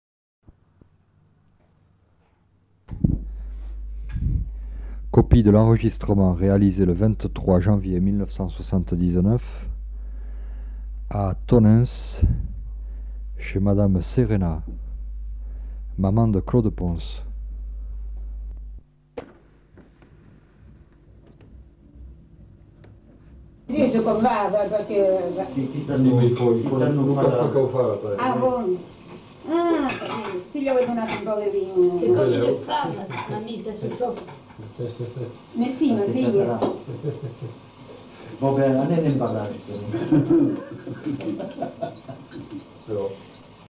Annonce
Aire culturelle : Marmandais gascon
Lieu : Tonneins
Genre : parole